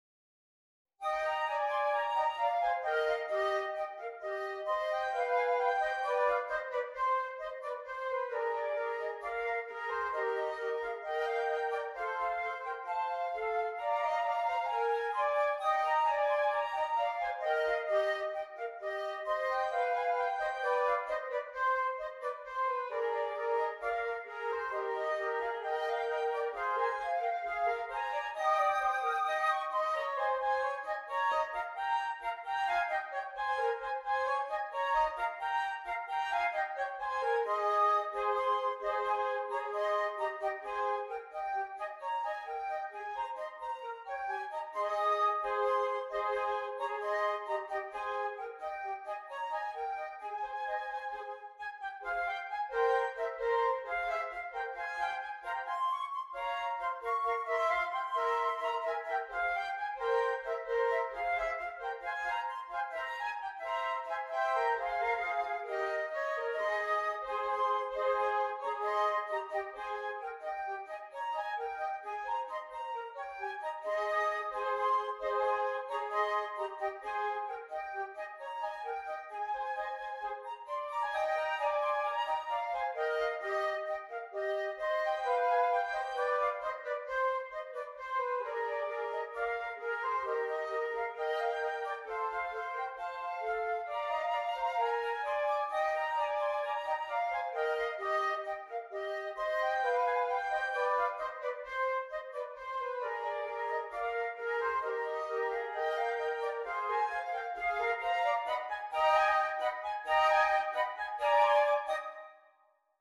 3 Flutes